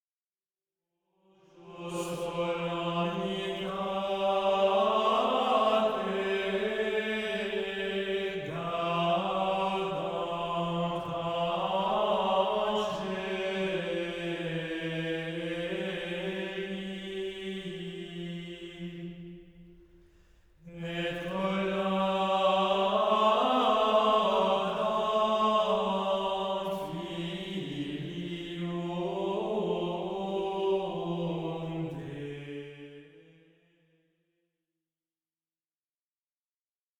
Plain-chant et polyphonies du 14e siècle
Introït